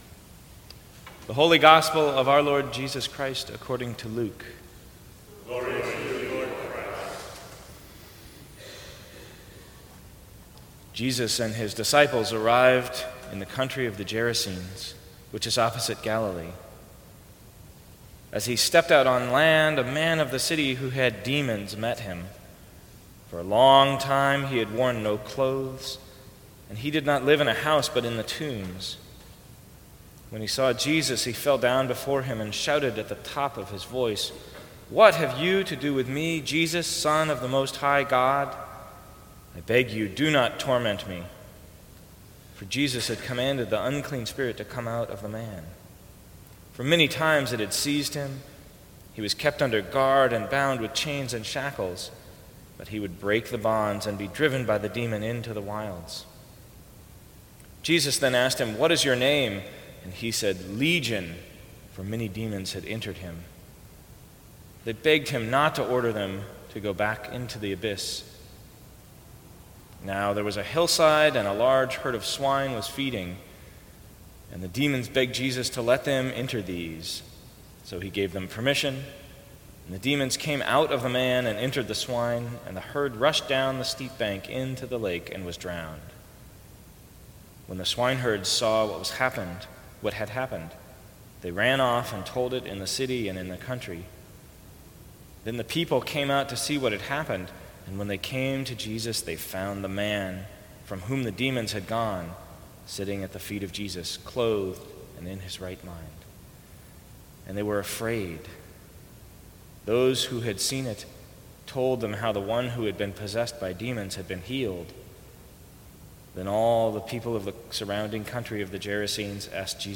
Sermons from St. Cross Episcopal Church What really are our demons?